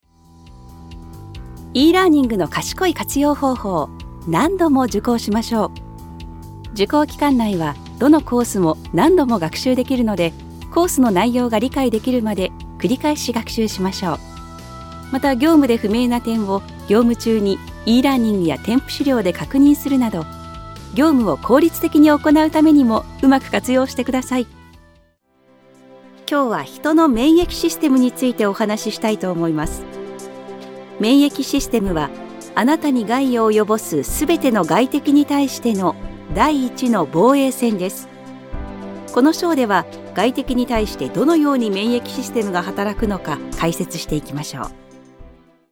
Natürlich, Vielseitig, Zuverlässig, Warm, Sanft
E-learning
Her voice is known for its empathetic and believable quality, making it ideal for connecting with audiences on a deeper level.